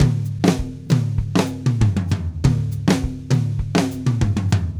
Drumset Fill 13.wav